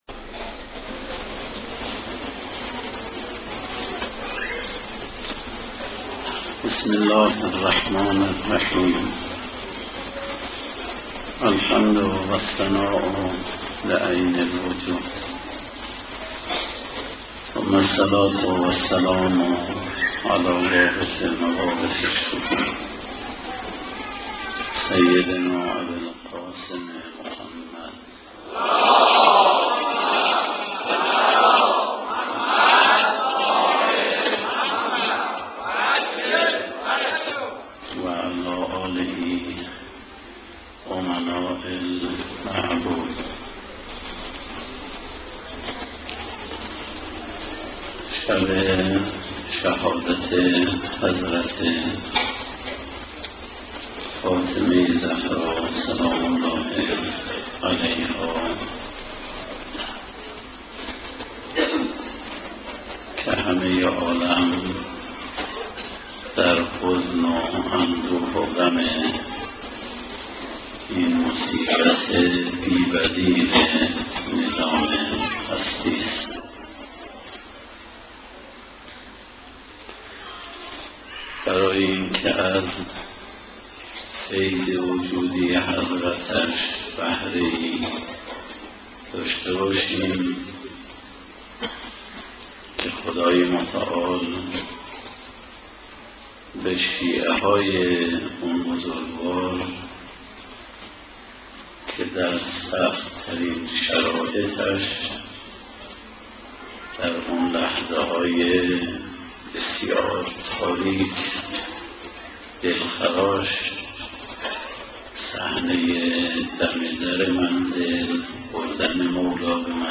سخنرانی فاطمیه 1391